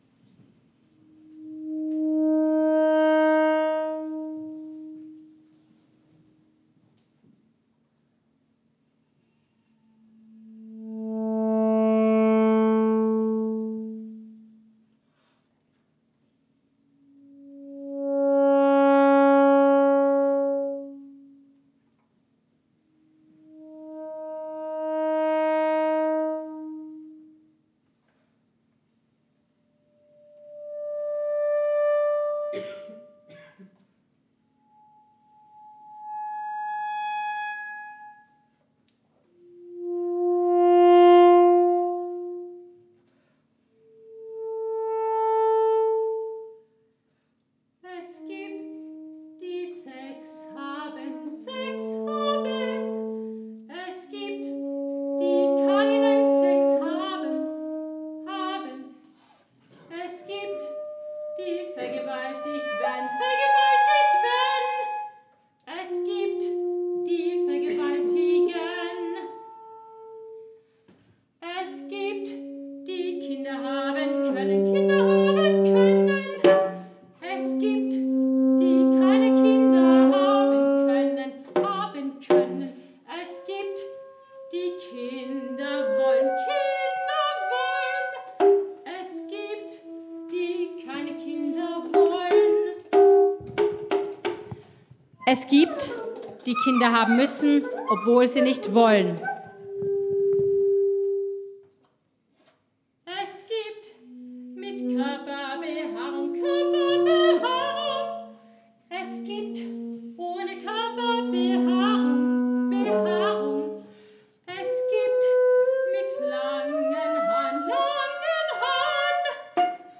für Sopran und Altsaxophon
Livemitschnitte